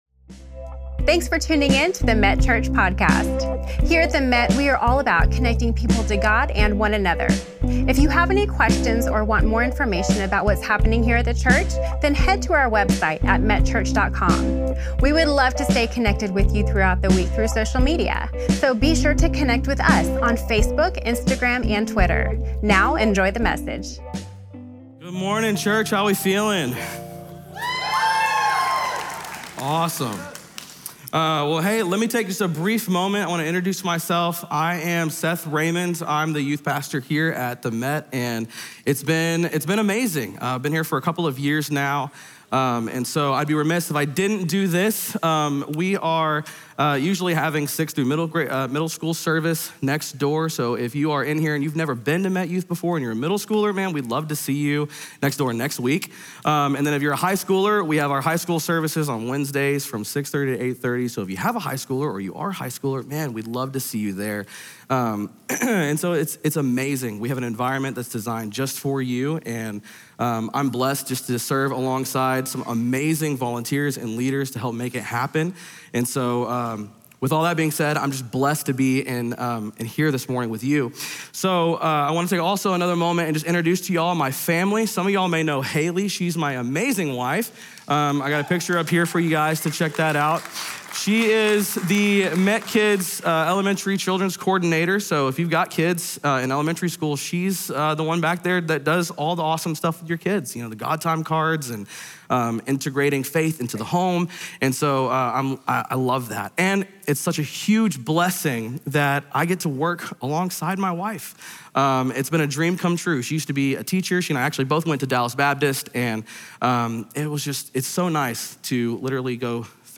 The Last Sermon of 2025